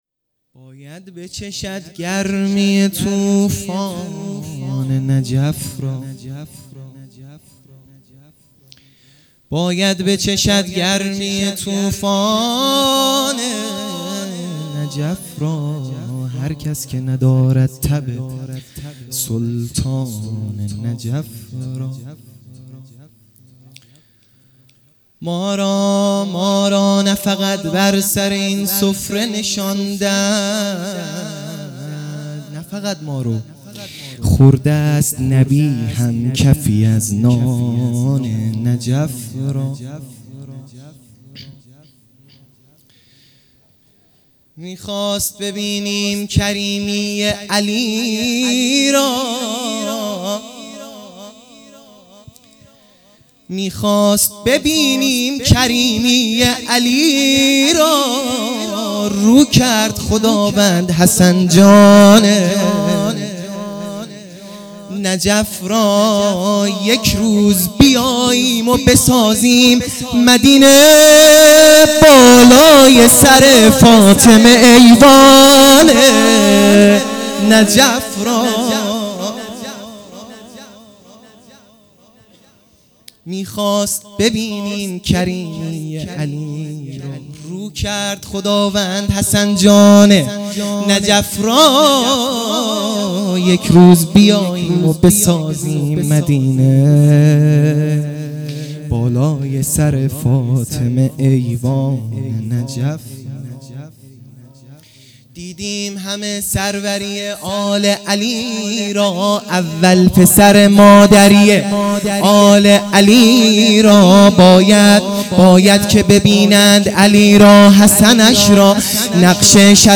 مدح
میلاد امام حسن علیه السلام